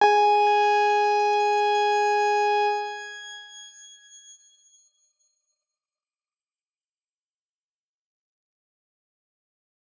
X_Grain-G#4-mf.wav